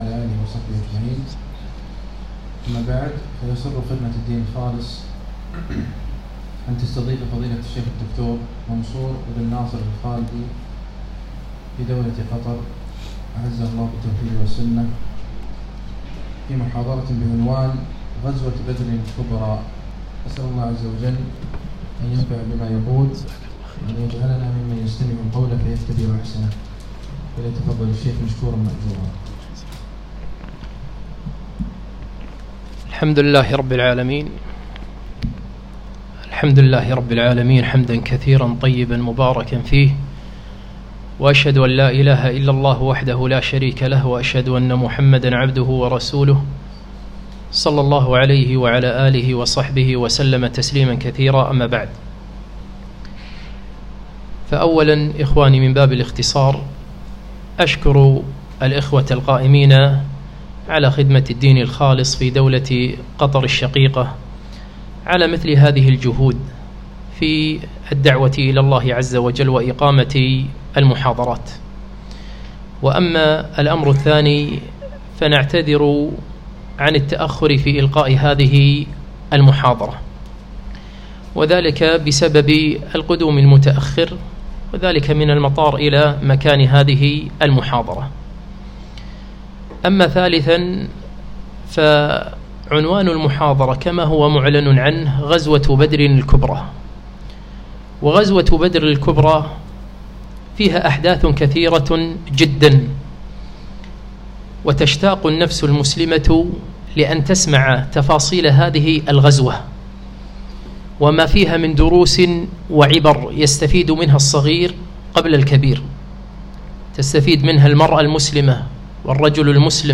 محاضرة - غزوة بدر الكبرى -المجلس الأول-